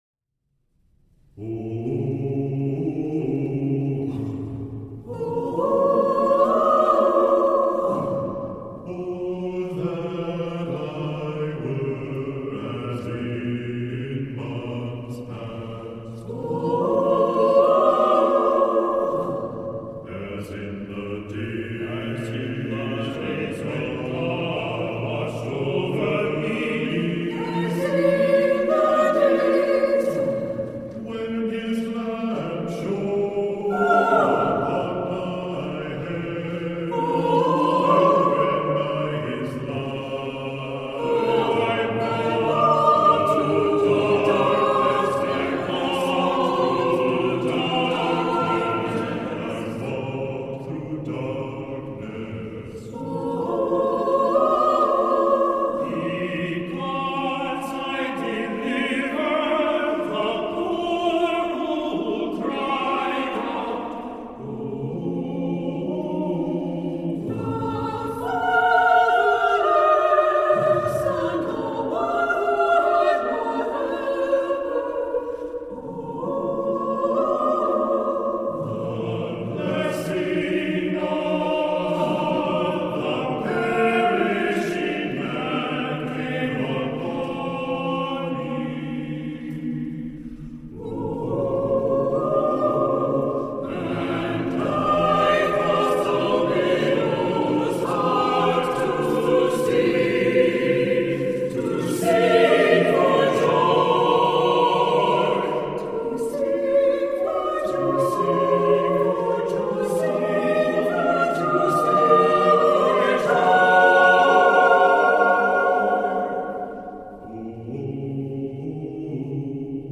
SATB chorus, unaccompanied [c. 3:00]